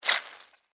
Звук паука кусающего челюстью